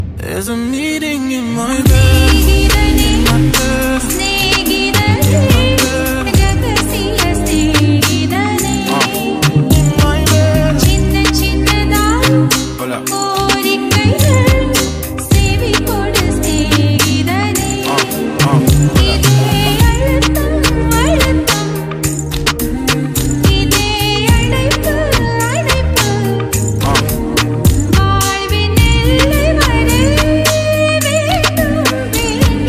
vibrant, remixed track